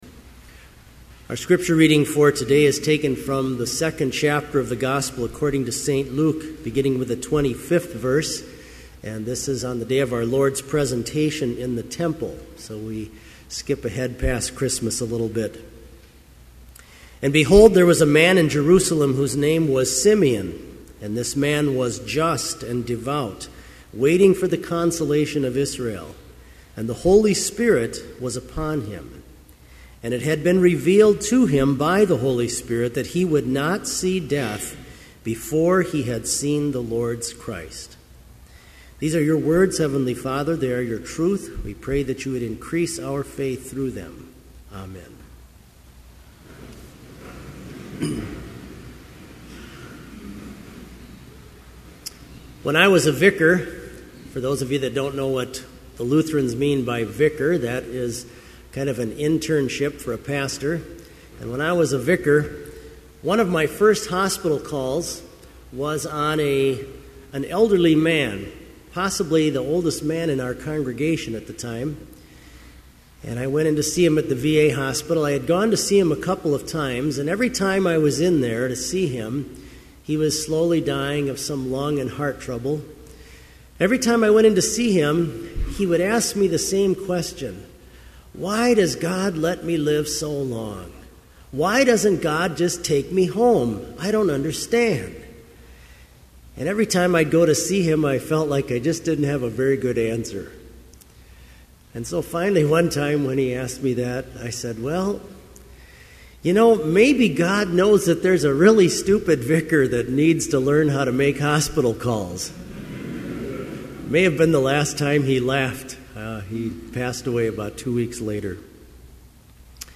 Complete service audio for Chapel - November 28, 2011